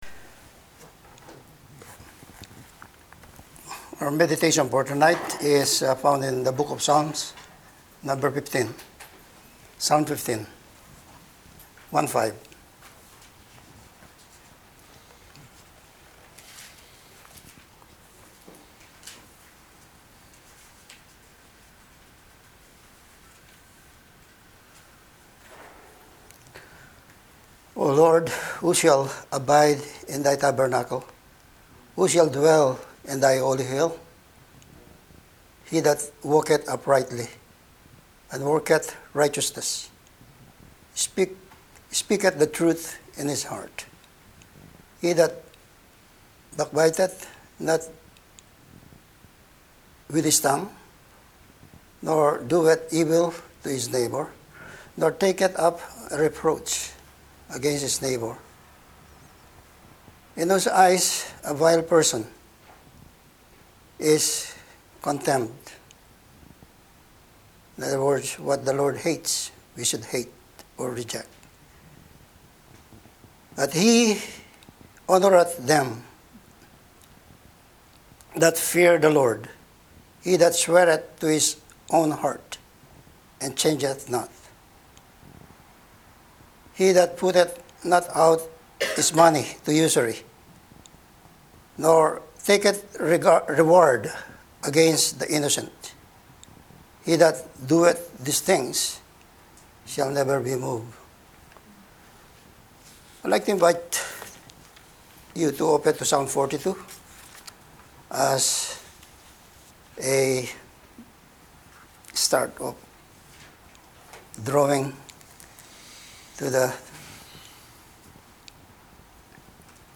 Passage: Psalm 15 Service Type: Sunday Evening Service « ‘It is appointed unto men once to die